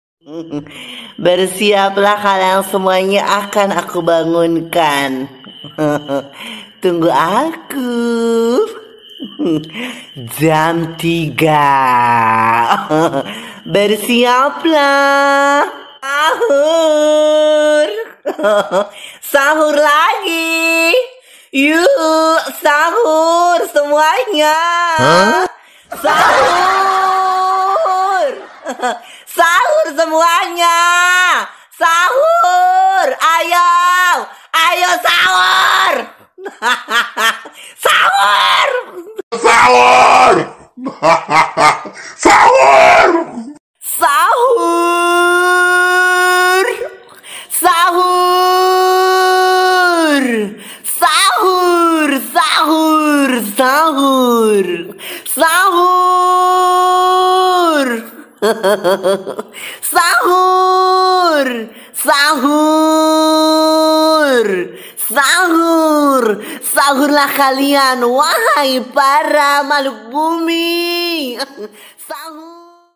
Nada alarm Sahur Ramadhan Alarm bangunin sahur Remix TikTok
Kategori: Nada dering